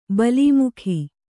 ♪ balī mukhi